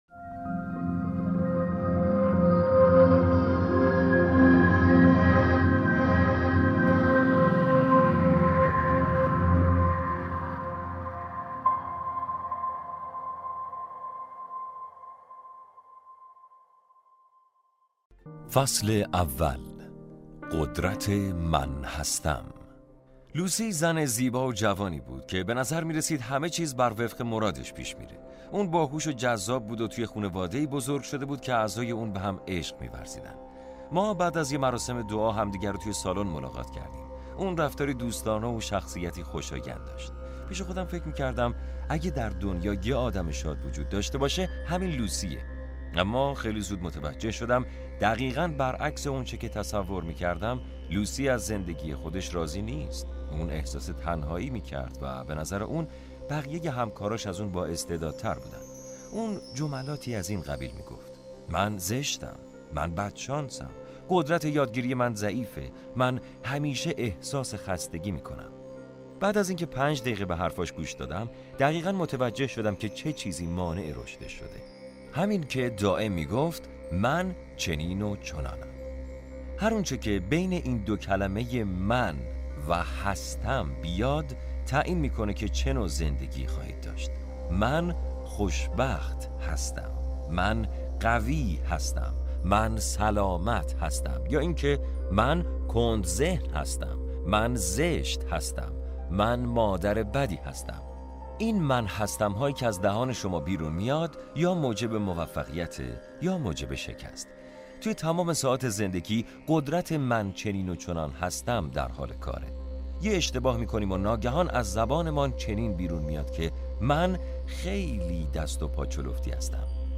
فصل اول کتاب صوتی قدرت من هستم!